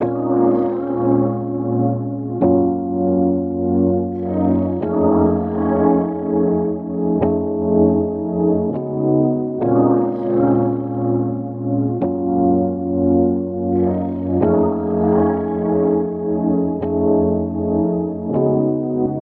Rotating two speakers at different speeds creates a swirling, chorus-like effect.
After Rotary PRO